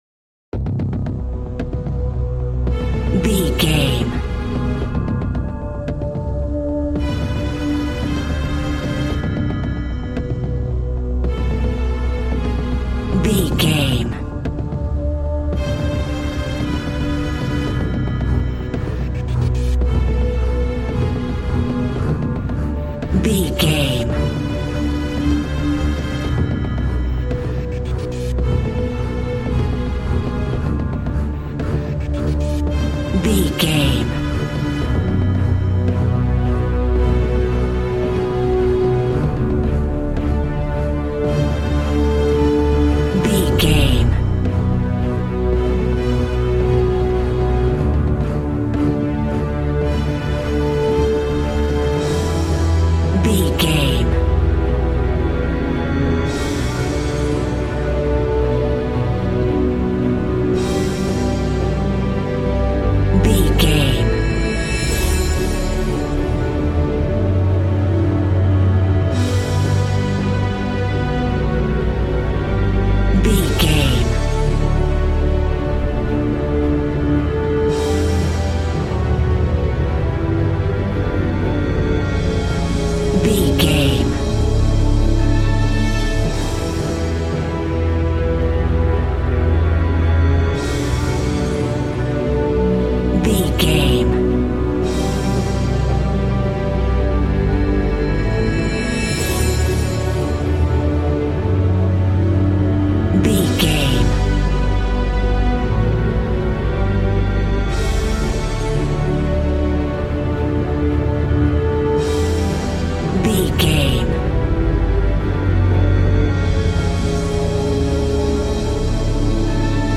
Epic / Action
Fast paced
In-crescendo
Uplifting
Aeolian/Minor
dramatic
powerful
strings
brass
percussion
synthesiser